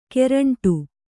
♪ keraṇṭu